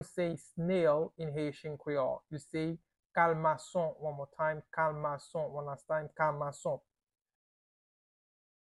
Listen to and watch “Kalmason” audio pronunciation in Haitian Creole by a native Haitian  in the video below:
How-to-say-Snail-in-Haitian-Creole-Kalmason-pronunciation-by-a-Haitian-teacher.mp3